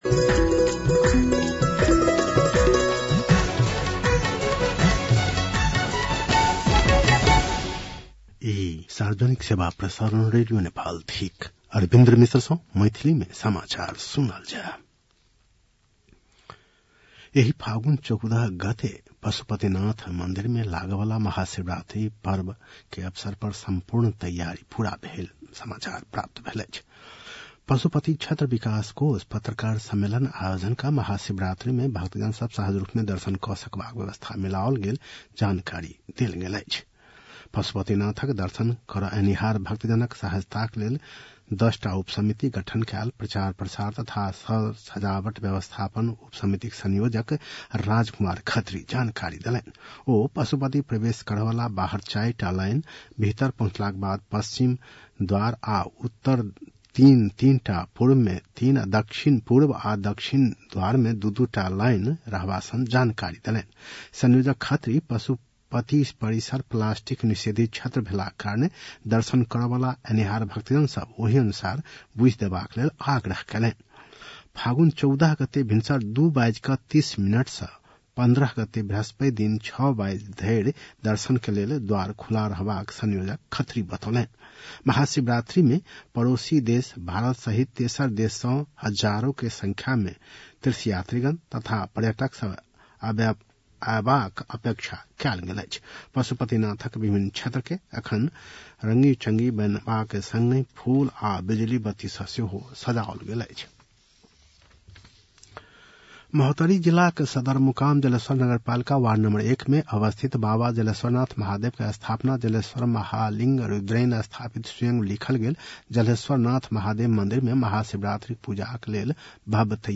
मैथिली भाषामा समाचार : १४ फागुन , २०८१